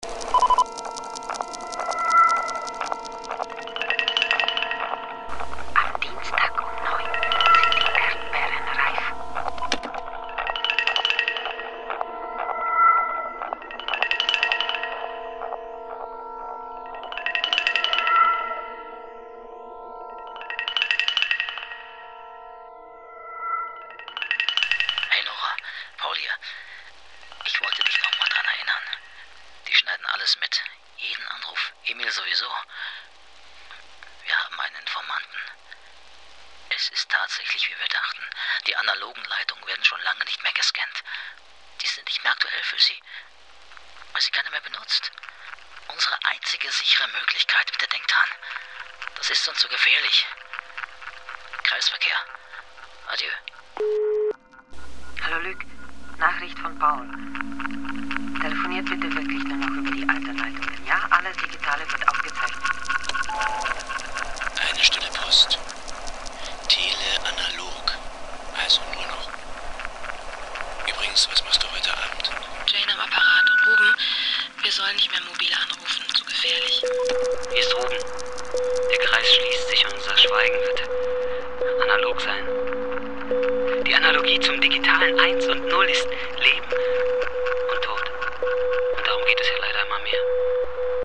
Also Tuten, Spulen, Rauschen, Piepen, Knacksen und ähnliches.
Ausschnitte der Radiofassung: